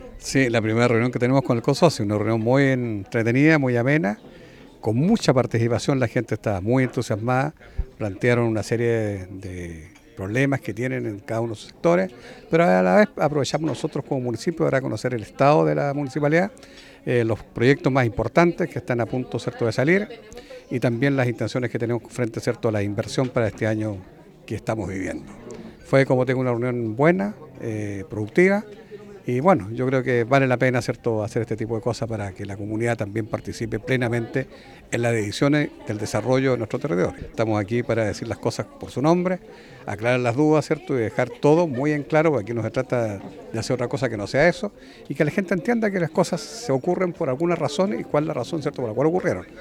El miércoles recién pasado, la sala de sesiones del municipio de Osorno fue el escenario de la primera reunión del año del Consejo de Organizaciones de la Sociedad Civil, COSOCI.
Por su parte, el alcalde de Osorno, Jaime Bertin, destacó que esta reunión no solo sirvió para atender las preocupaciones de los representantes de las organizaciones sociales, sino también para presentar los proyectos que el municipio tiene planificados para la comuna.